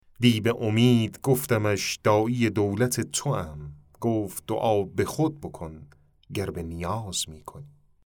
(دکلمه)